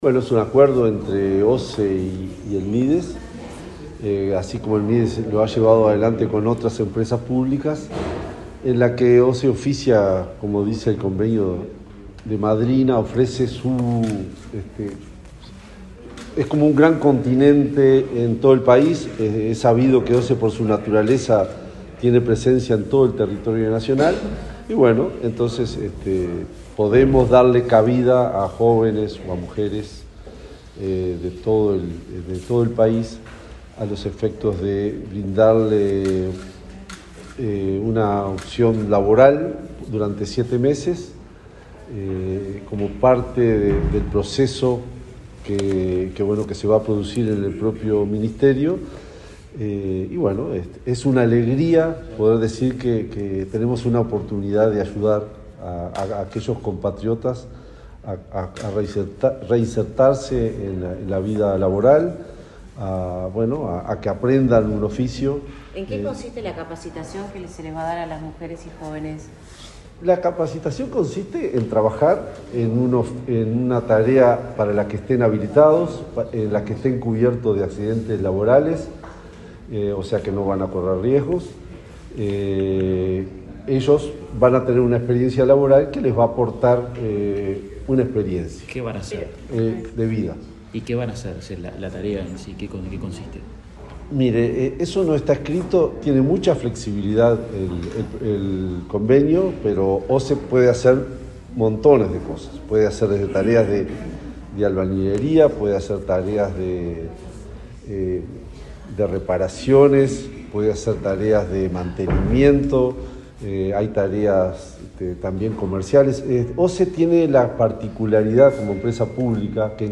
Declaraciones del presidente de OSE, Raúl Montero
El presidente de la OSE, Raúl Montero, efectuó declaraciones a la prensa, antes de la firma de un convenio marco con el Ministerio de Desarrollo